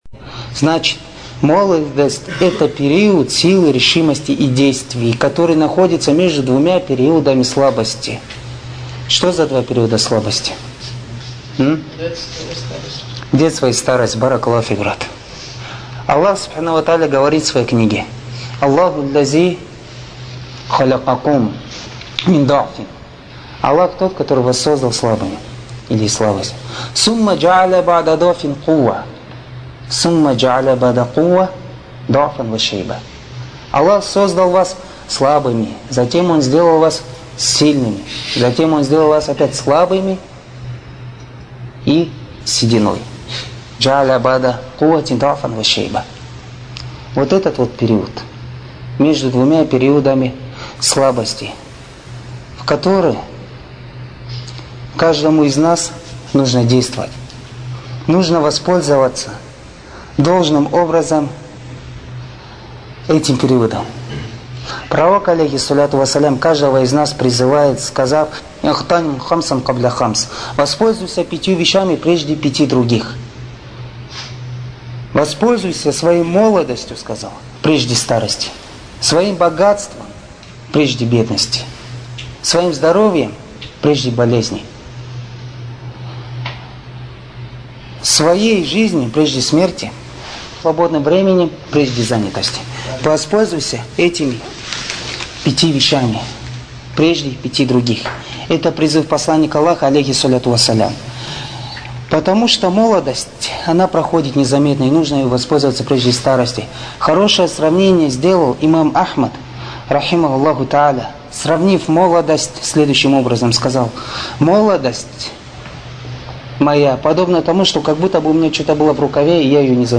Материал:Лекция шейха Салиха бин АбдульАзиз Содержание:описание каким образом следует требовать знания